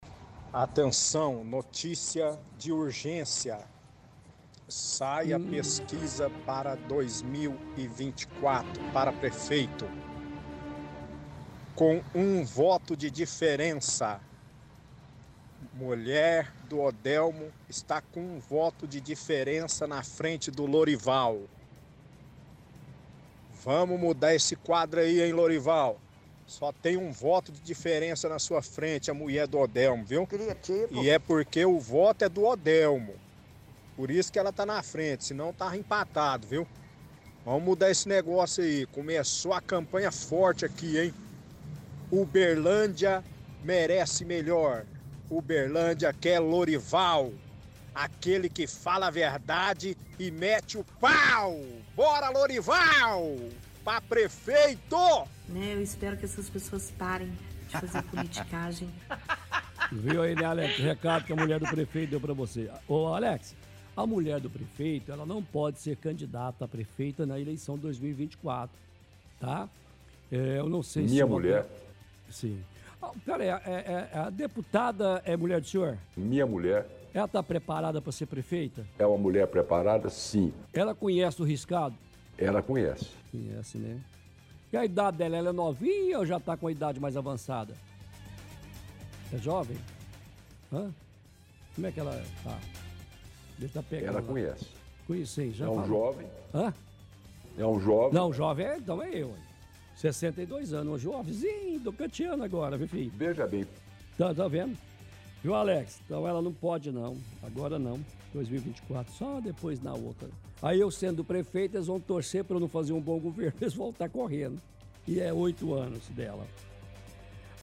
– Debocha da deputada com áudios antigos.